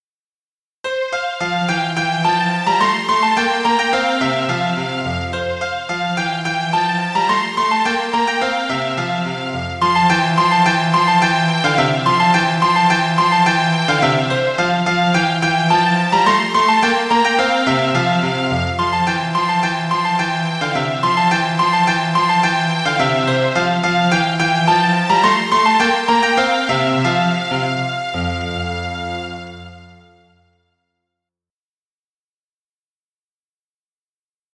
クラシック